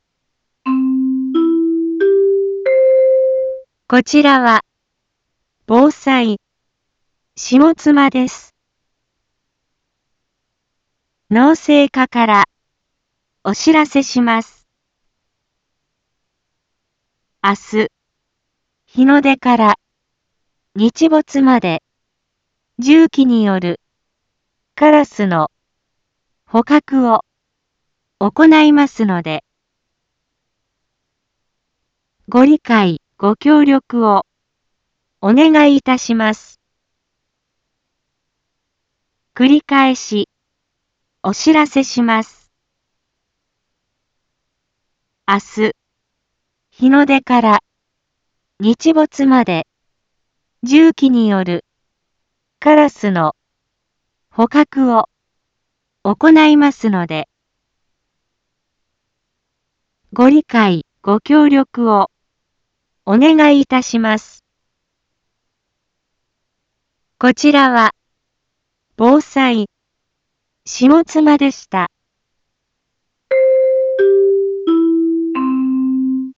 Back Home 一般放送情報 音声放送 再生 一般放送情報 登録日時：2023-02-04 18:01:17 タイトル：有害鳥獣捕獲についてのお知らせ インフォメーション：こちらは、防災、下妻です。